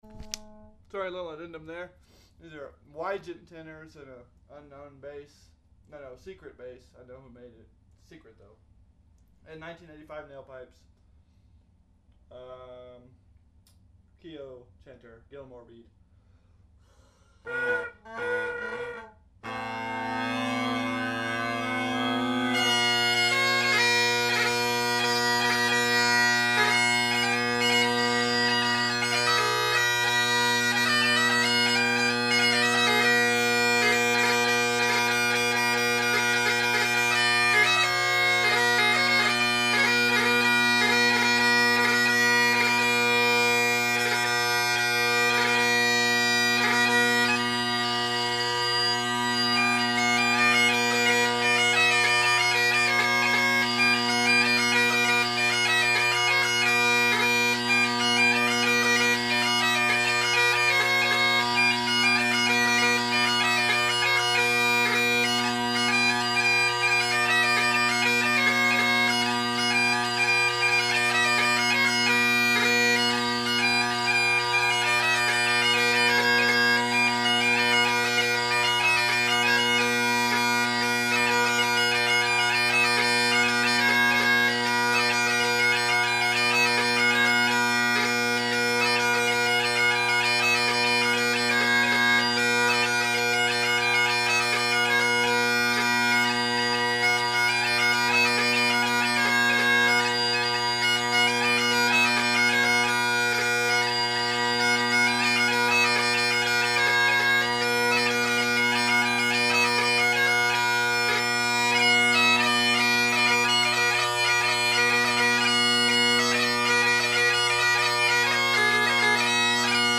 Great Highland Bagpipe Solo
Here I have for your listening endurement (I sorta messed up the recording [a bit drone heavy], I think the bass drone was sitting right in front of the mic) enjoyment some 4 parted 6/8 jigs.
I talk a bit at first and fiddle about during the set, but if the pipes are still going there’s more tunes to hear. You’ll know the end as you’ll hear the owner of the pipes ask a question.
Boy.. those 1987 Naill drones sound like a bunch of angry bees. It’s certainly not a mellow sound, but it’s not what I would call “bad”…